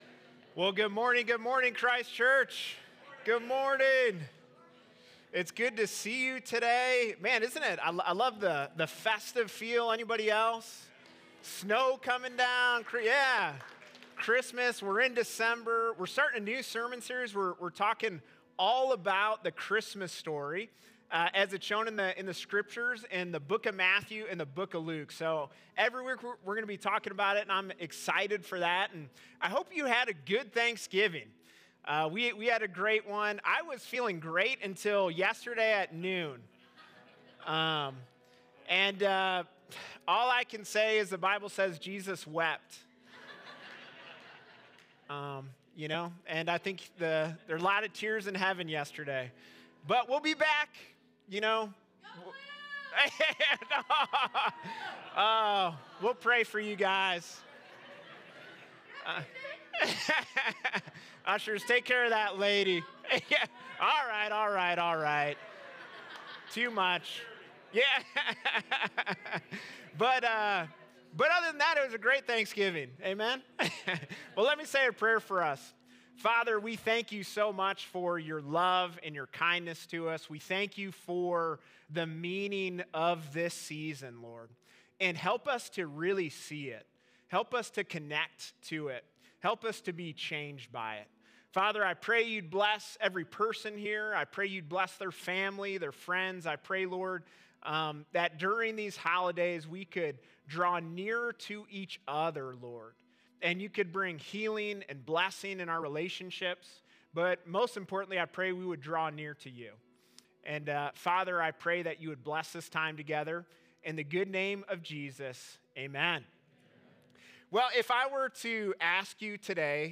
West Campus